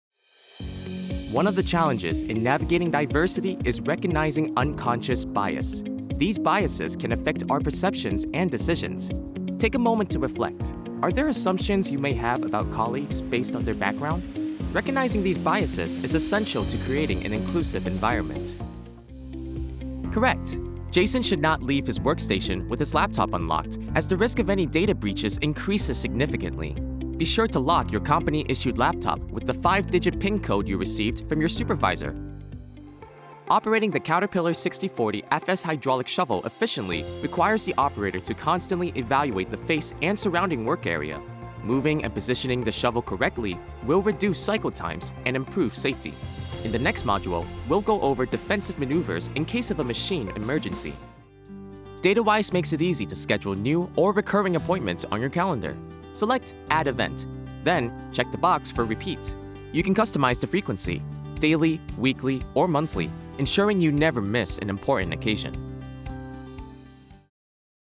Teenager, Young Adult, Adult
e-learning
non-broadcast level home studio